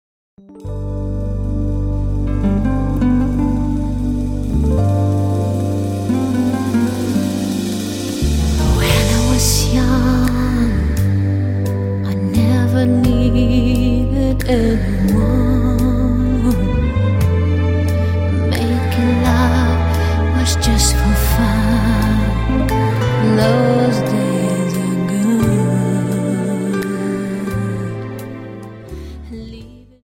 Dance: Slow Waltz